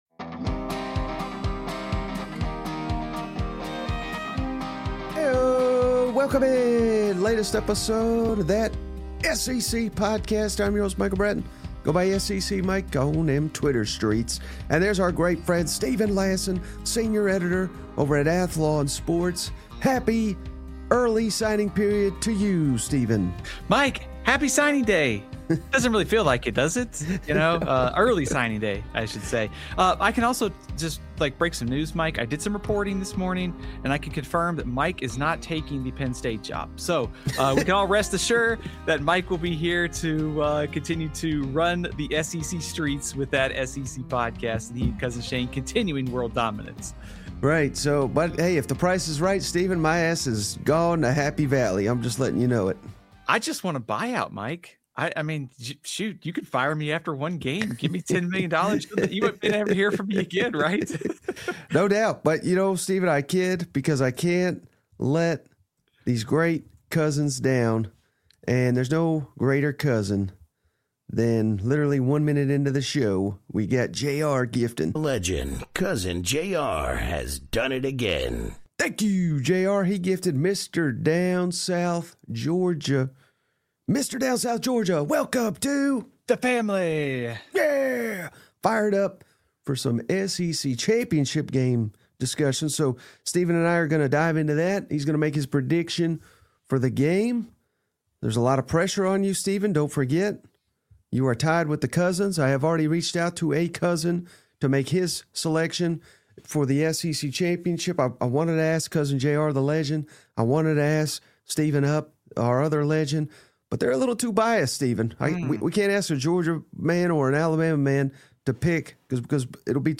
Download - Florida Gators 2024 Read & Reaction Magazine Preview interview